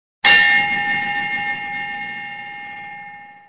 Tags: martillo